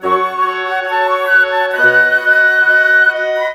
Rock-Pop 01 Winds 02.wav